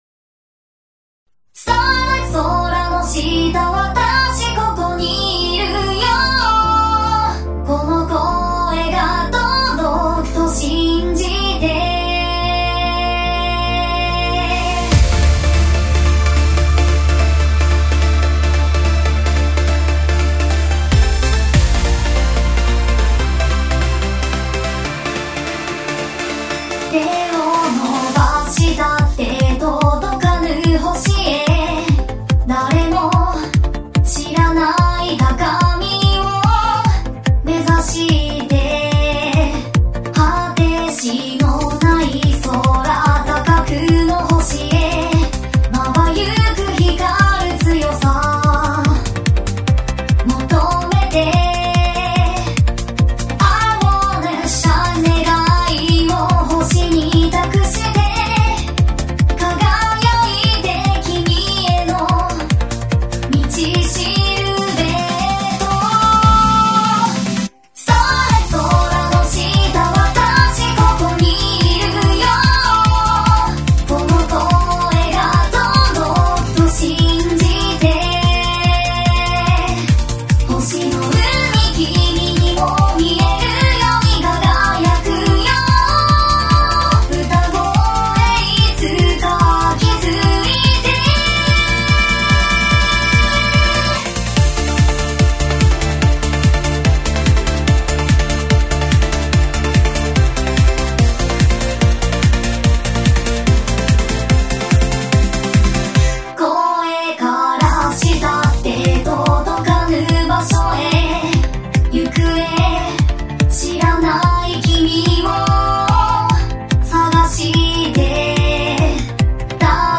Inst(OGG) BGM(OGG)